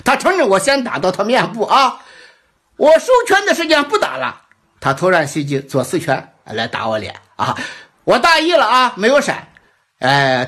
IA per Voiceover Divertenti da Gaming
Genera narrazione IA espressiva e spassosa per i tuoi momenti salienti di gaming, meme e clip di streaming istantaneamente.
Text-to-Speech
Tempismo Comico
Voci Espressive